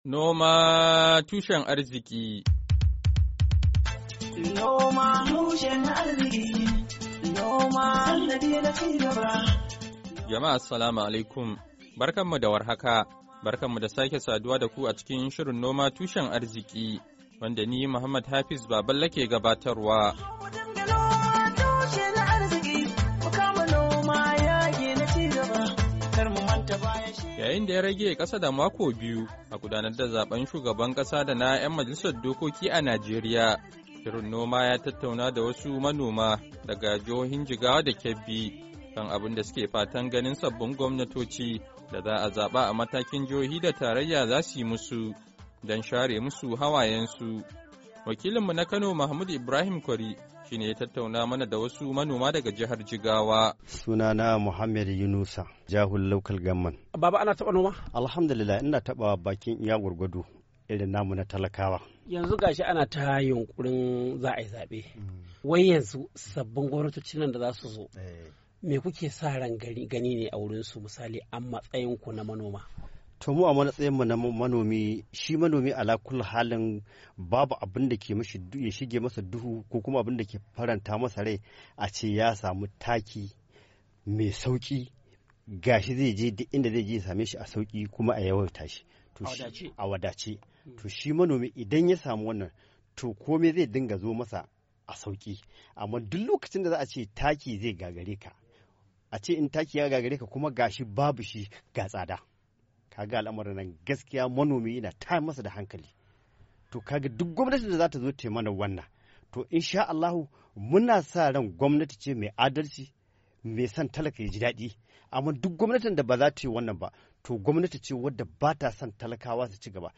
Shirin na wannan makon, ya tattauna da wasu manoma daga jihohin Jigawa da Kebbi, kan abun suke fatan ganin sabbin gwamnatoci da za’a zaba a Najeriya daga matakin jihohi da tarayya za su yi musu, don share mu su hawayen su.